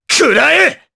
Dimael-Vox_Attack3_jp.wav